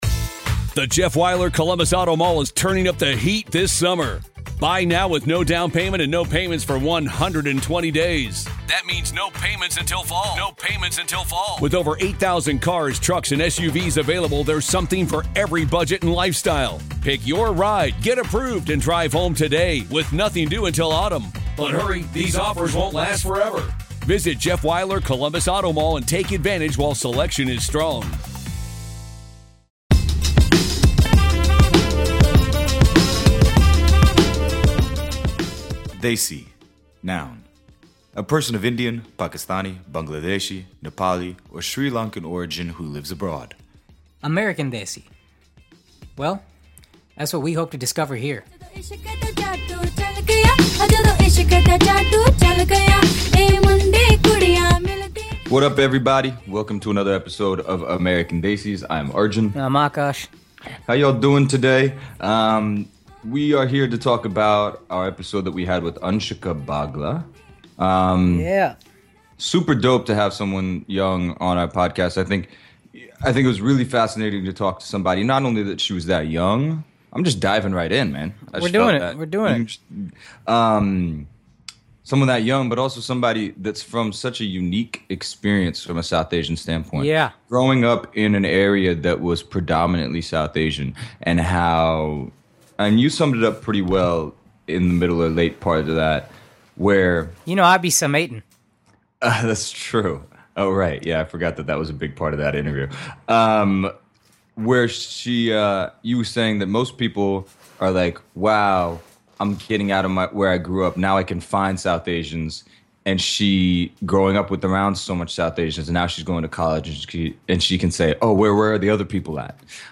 As you can tell by the title, the mood faded towards the end :)